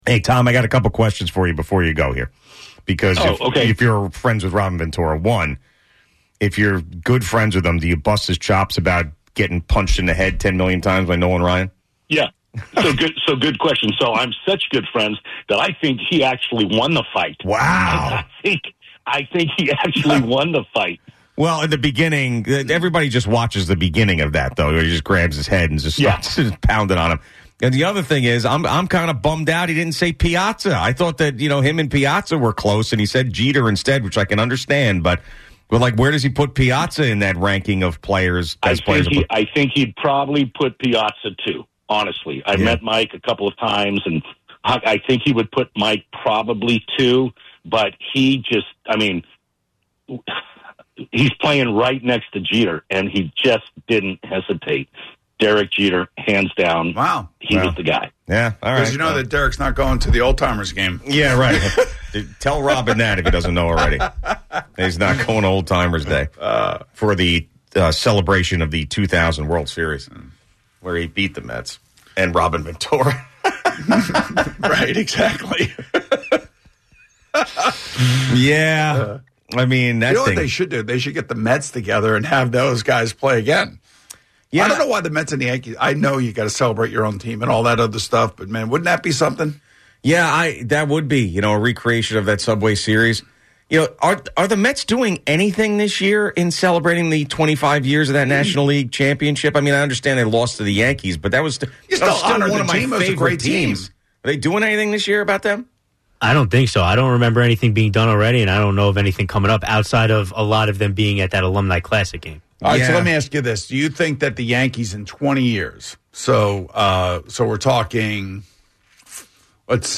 A caller is close friends with Robin Ventura so Gio asks him a few Ventura-related questions.